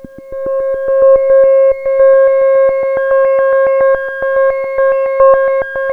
JUP 8 C6 11.wav